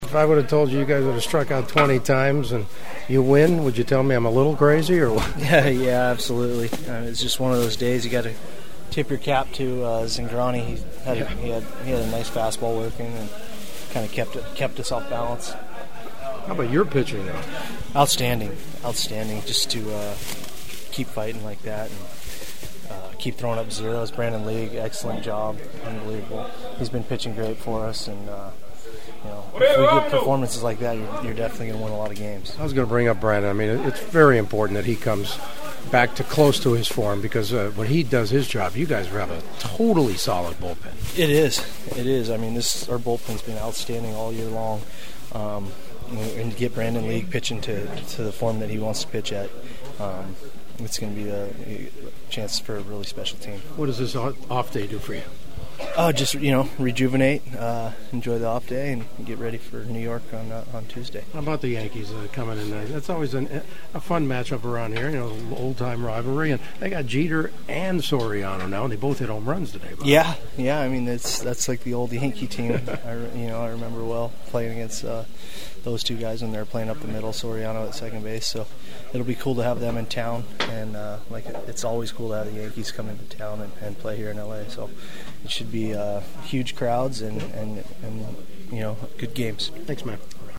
The following are my postgame clubhouse interview including some preview thoughts on the Yankees invading Dodger Stadium for 2 nights starting on Tuesday.
Dodgers utility specialist Nick Punto: